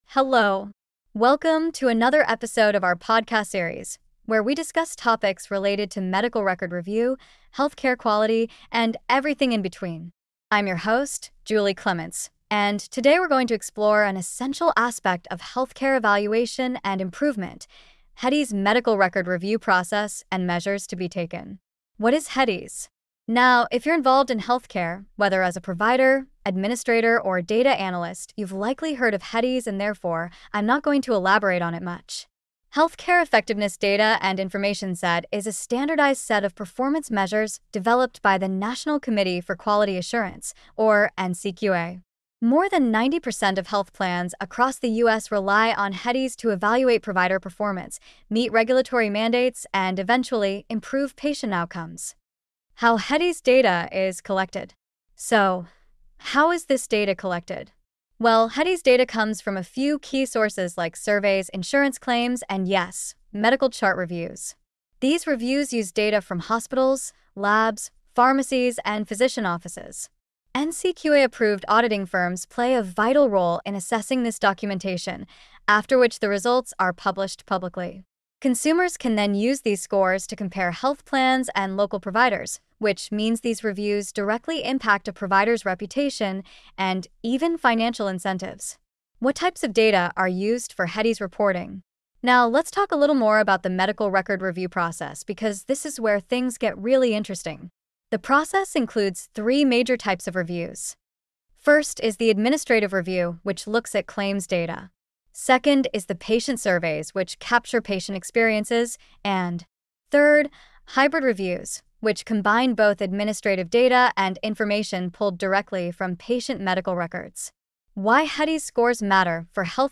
Disclaimer: This podcast features human-written content, narrated using AI-generated voice.